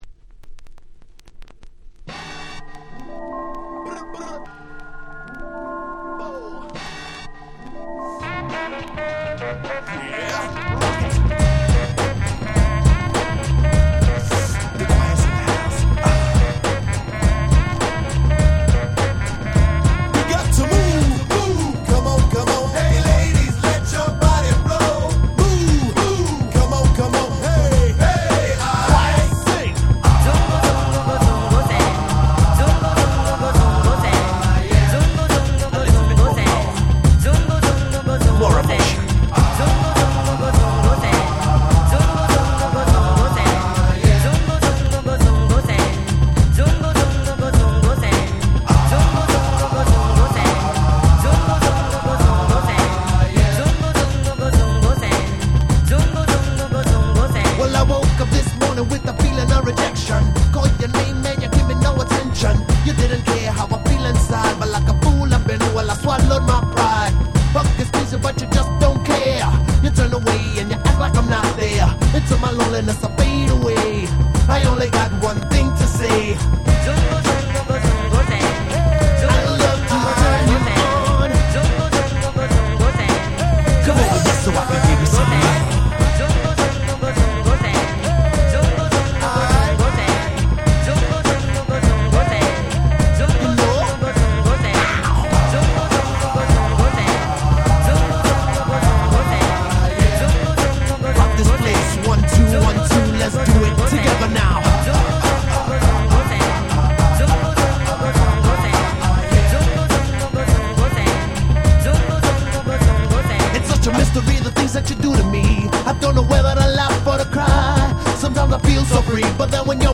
94' Smash Hit Hip Hop !!
Boom Bap